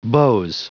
Prononciation du mot beaux en anglais (fichier audio)
Prononciation du mot : beaux